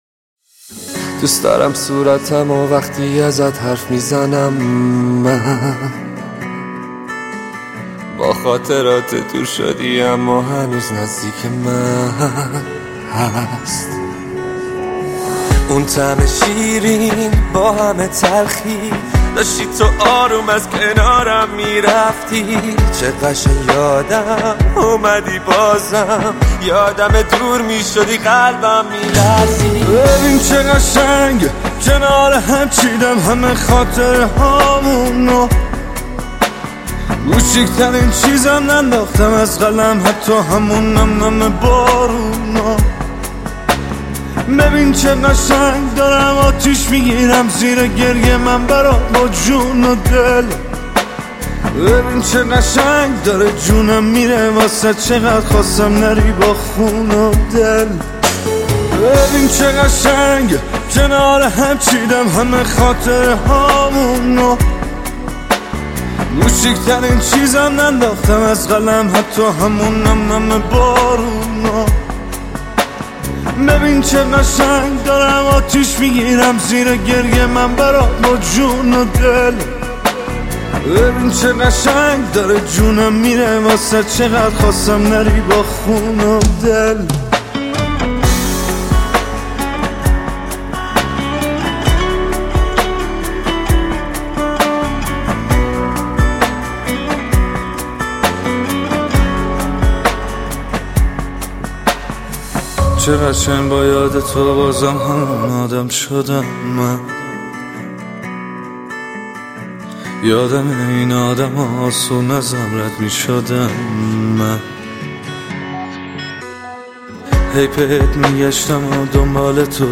Мусиқа ва тарона Эрон мусиқаси